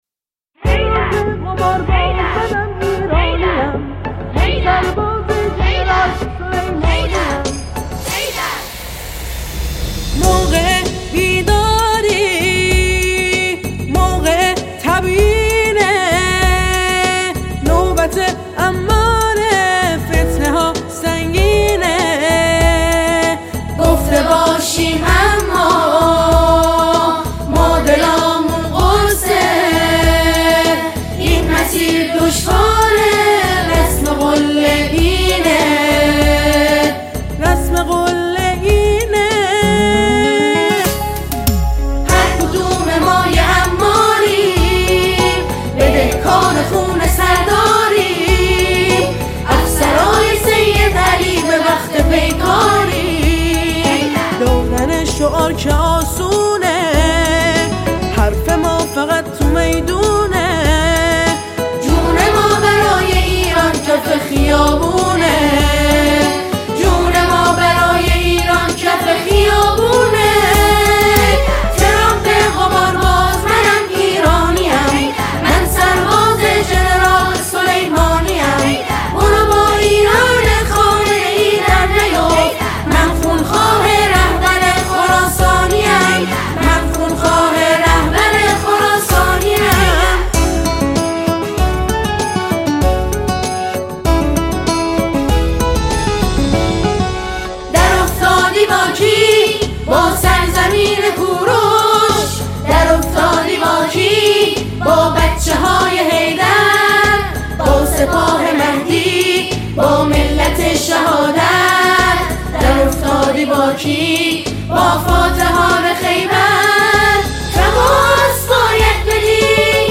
ژانر: سرود ، سرود انقلابی